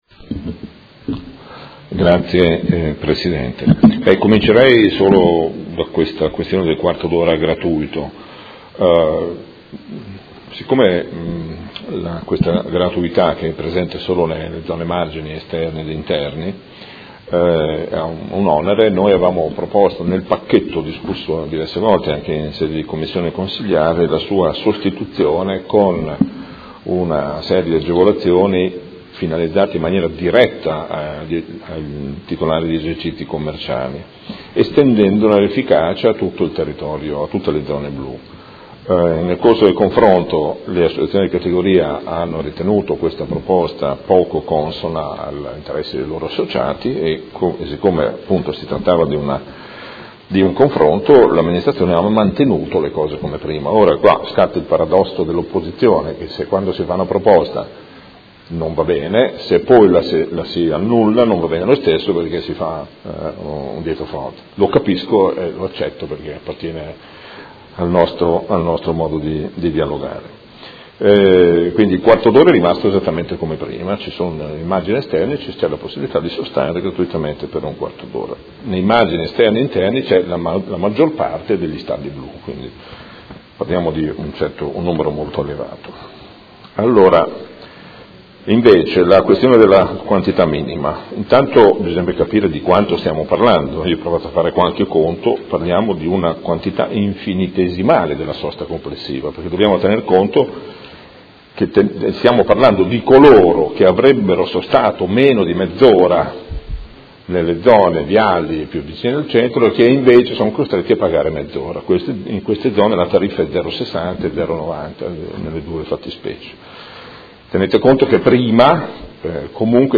Seduta del 19/01/2017 Interrogazione del Gruppo F.I. avente per oggetto: Importi minimi per la sosta in alcune zone della città. Risponde l'Assessore